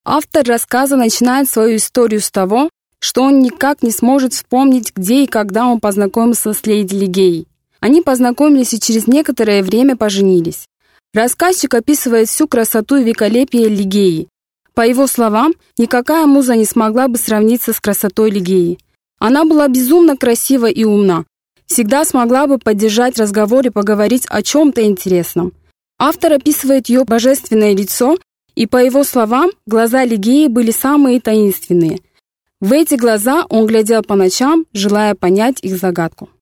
Yabancı Seslendirme Kadrosu